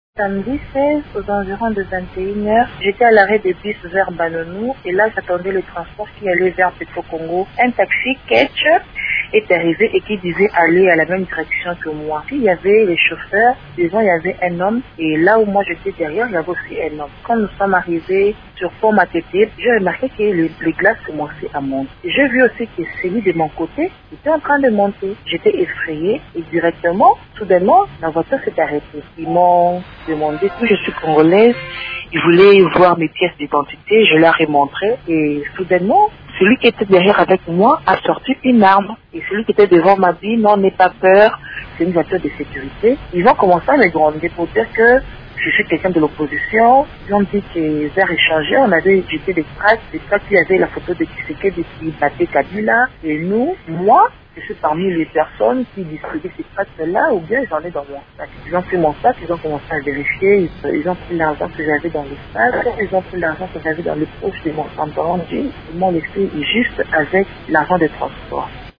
Vous pouvez écouter le témoignage d’une dame victime d’un braquage :